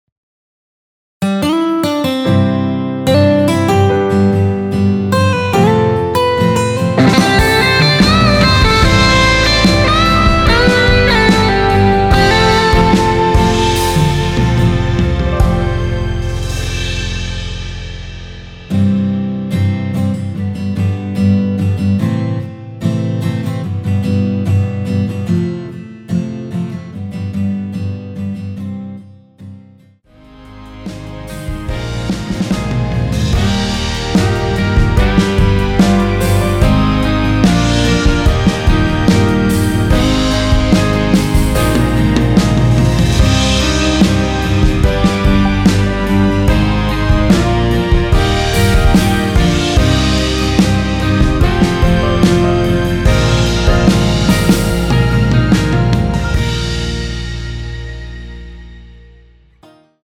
1절 “내 모든 걸 너에게 줄게” 다음 2절 후렴 부분인”세월 지나 많이 변했을 때~”로 진행 됩니다.
원키에서(-7)내린 (2절 삭제) MR입니다.
앞부분30초, 뒷부분30초씩 편집해서 올려 드리고 있습니다.